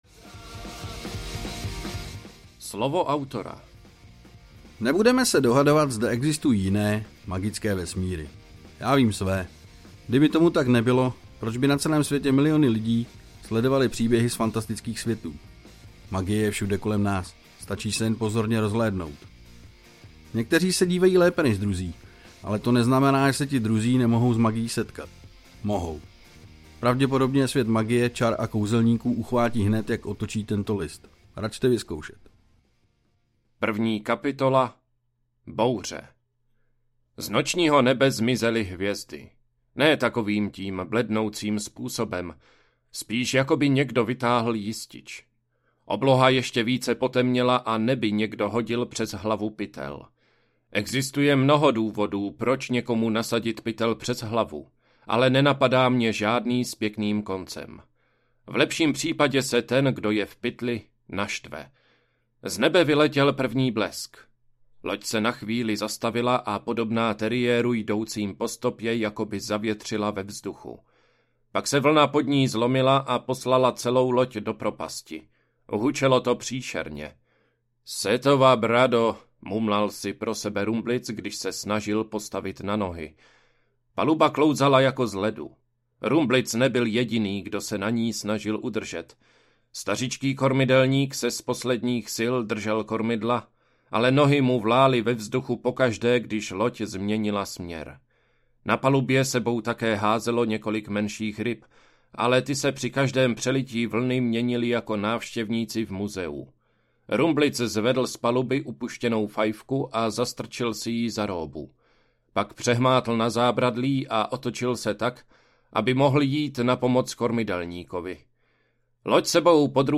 Záhadný grimoár audiokniha
Ukázka z knihy